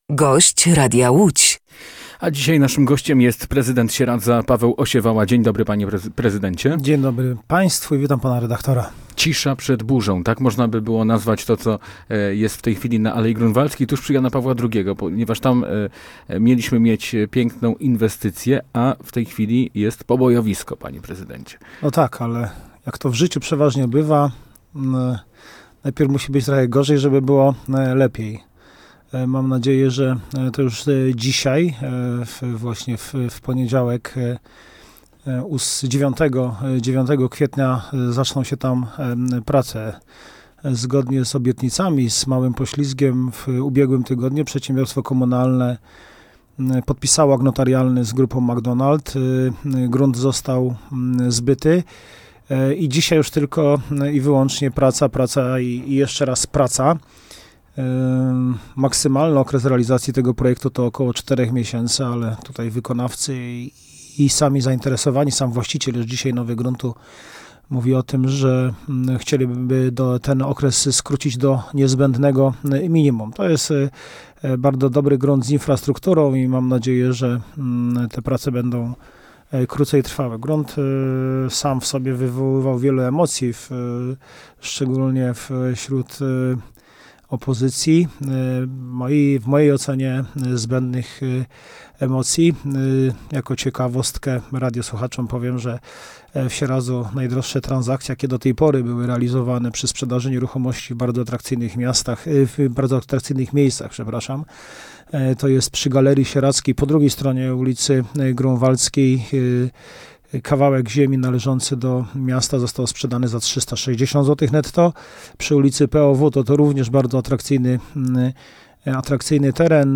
Gościem Radia Łódź Nad Wartą był prezydent Sieradza, Paweł Osiewała.